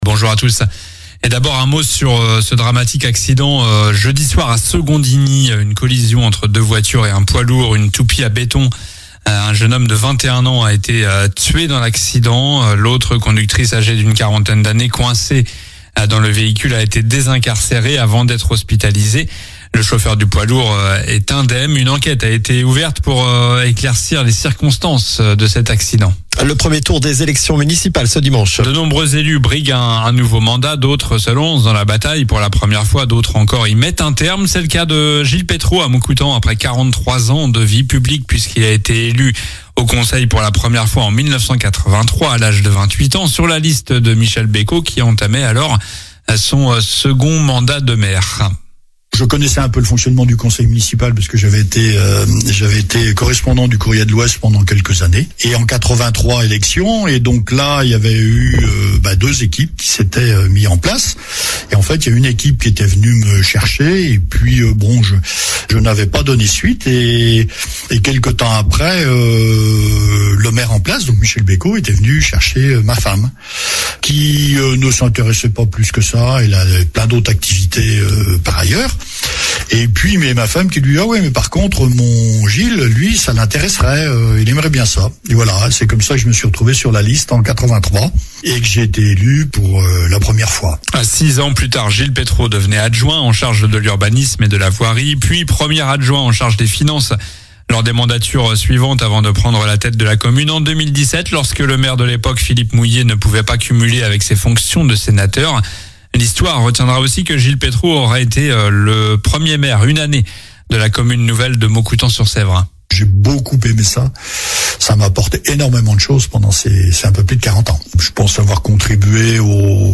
COLLINES LA RADIO : Réécoutez les flash infos et les différentes chroniques de votre radio⬦
Journal du samedi 14 mars 9h